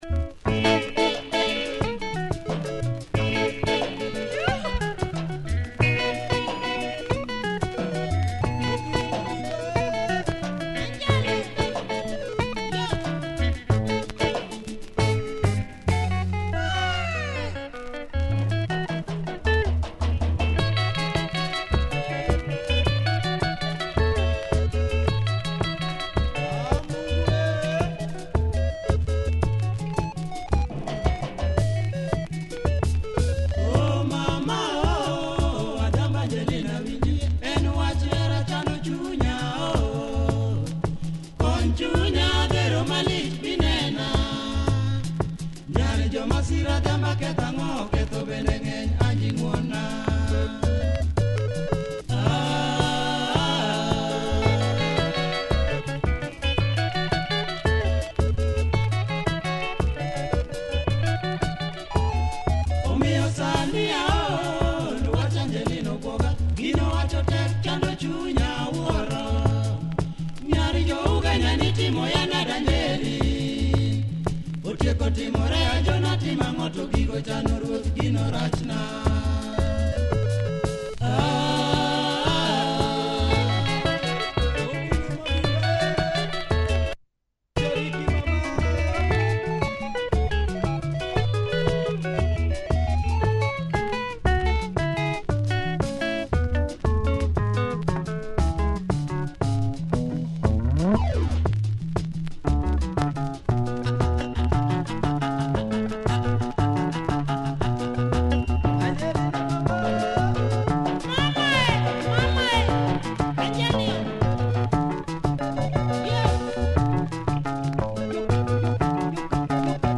Nice LUO Benga, cool organ